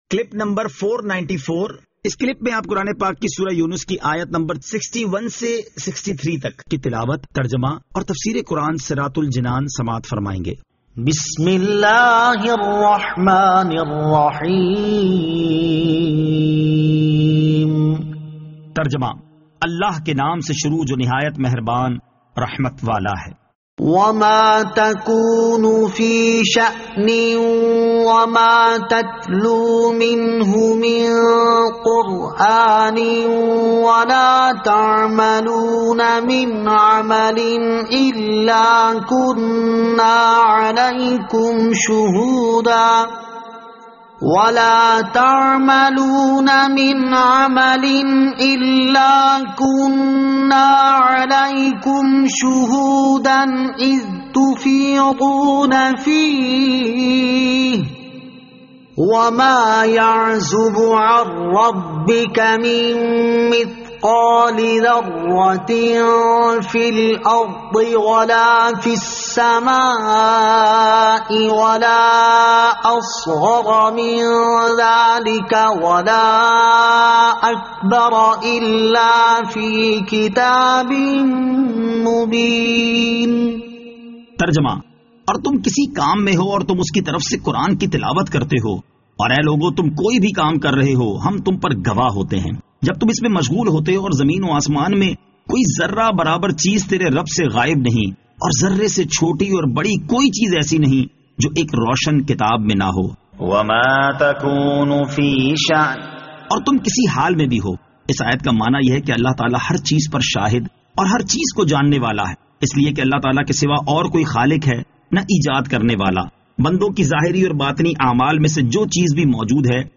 Surah Yunus Ayat 61 To 63 Tilawat , Tarjama , Tafseer